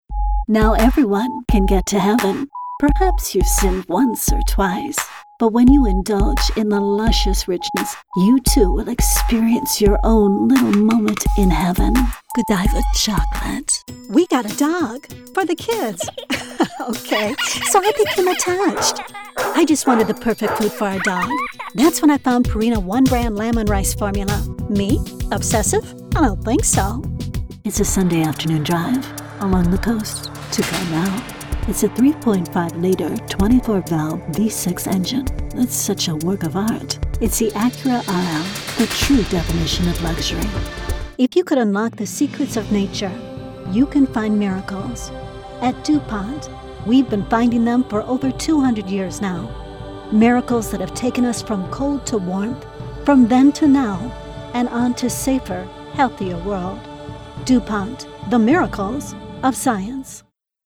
Amer. English, voice over, commercial, narration, documentary, Smooth Sophisticated Corporate Professional Warm Conversational unique sincere classy intelligent inviting appealing approachable,alto, relaxing, Soft spoken uplifting deep slightly raspy quality
middle west
Sprechprobe: Werbung (Muttersprache):